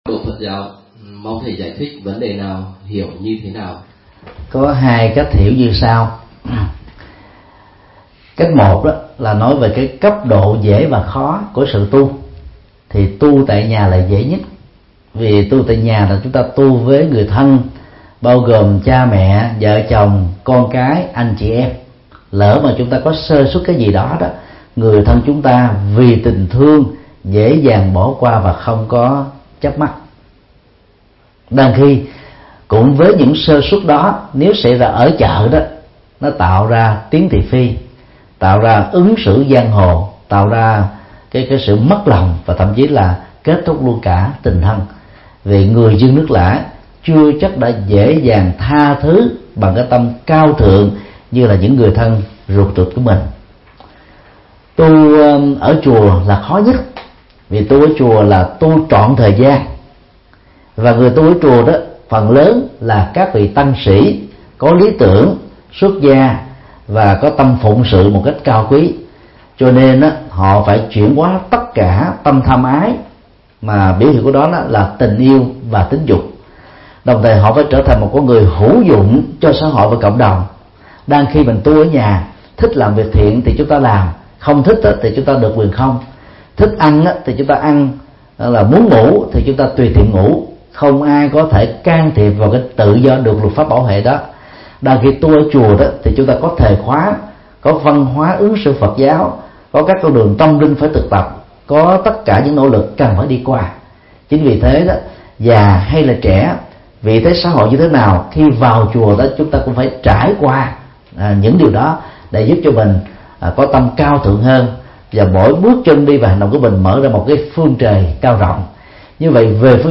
Vấn đáp: Tu tại gia – Tu chợ – Tu chùa